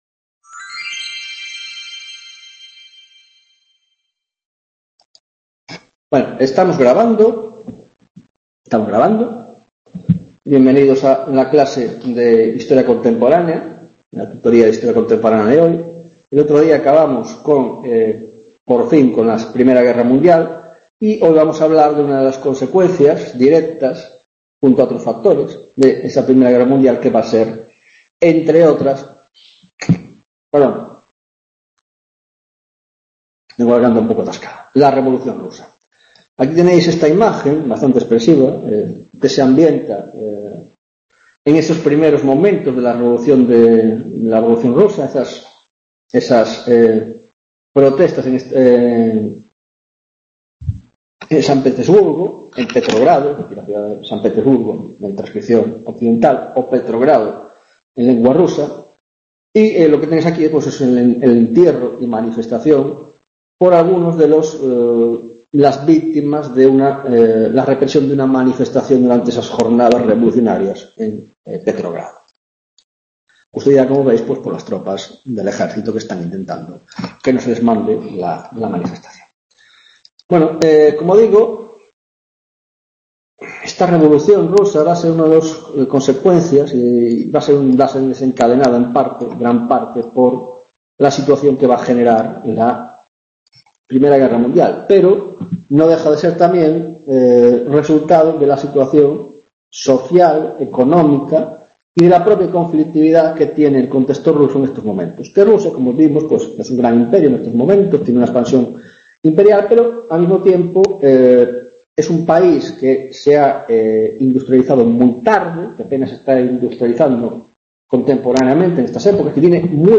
15ª tutoria de Historia Contemporánea - Revolución Rusa 1ª parte